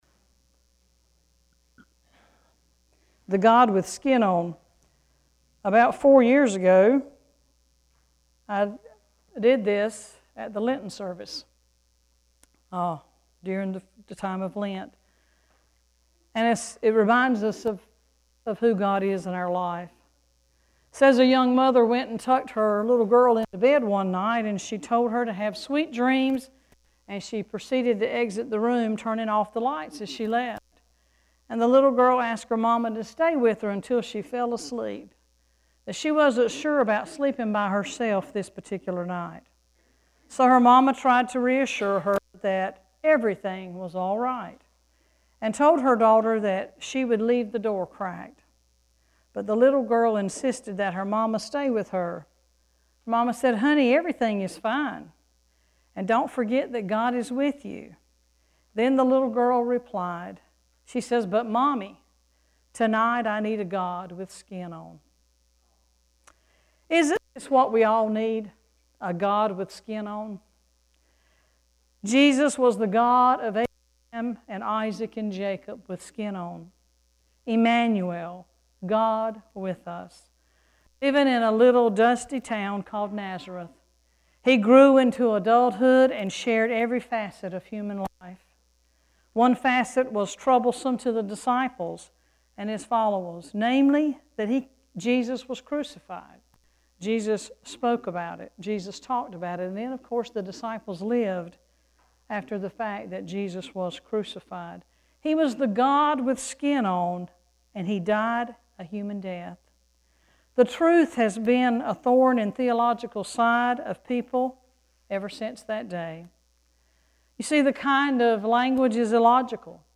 3-4-sermon.mp3